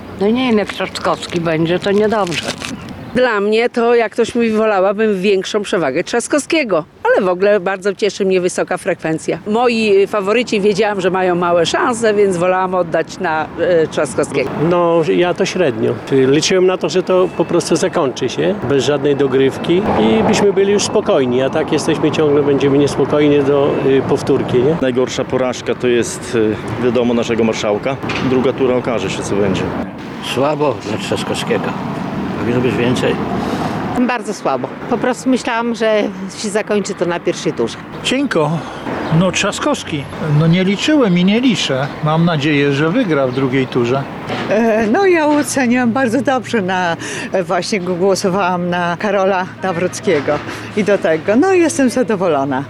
Po pierwszej turze wyborów nastroje wśród przechodniów zapytanych na ulicach Suwałk są wyraźnie podzielone – jedni cieszą się z sukcesu swojego kandydata, a inni nie kryją rozczarowania. Są też tacy, którzy liczyli na ostateczne rozstrzygnięcie już w pierwszej turze i z niepewnością spoglądają w stronę nadchodzącej dogrywki.
Sonda-po-wyborach.mp3